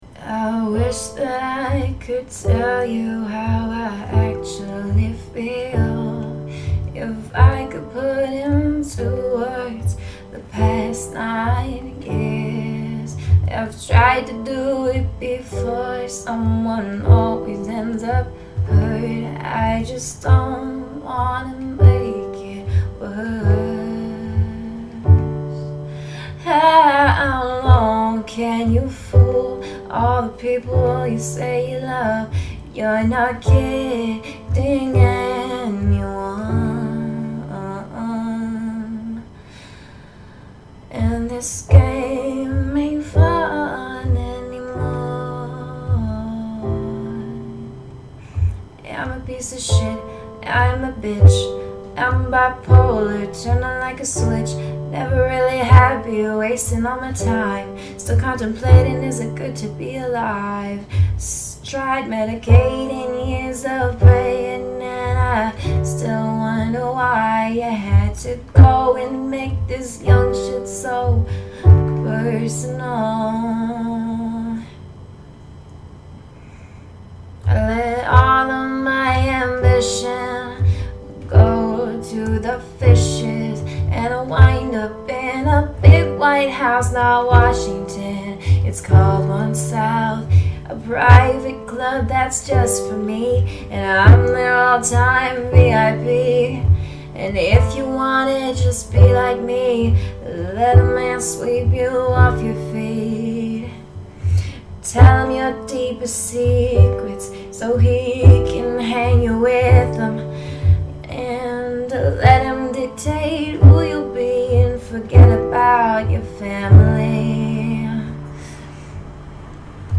I’m a Canadian born singer / songwriter.
Performed LIVE